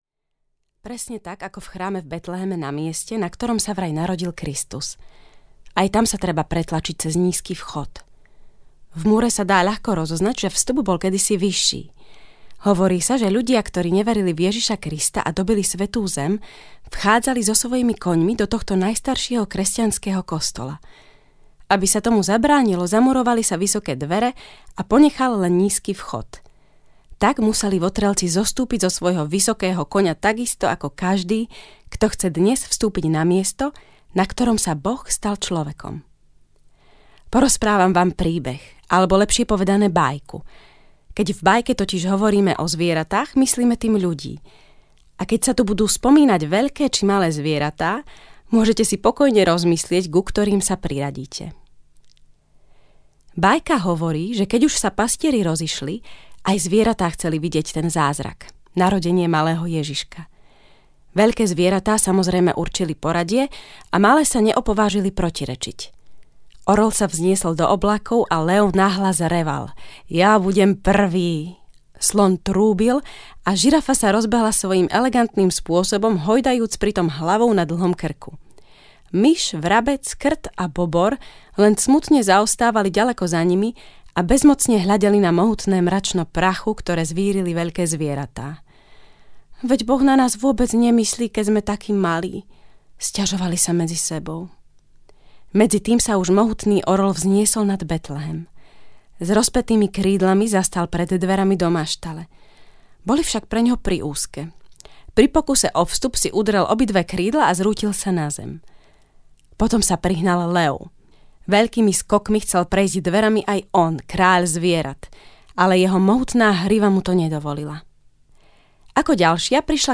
Najkrajšie vianočné príbehy audiokniha
Ukázka z knihy
Výber 24 príbehov, ktoré zozbieral nitriansky diecézny biskup a profesor cirkevných dejín Viliam Judák, spríjemní adventnú i vianočnú atmosféru. Číta herečka Gabriela Marcinková.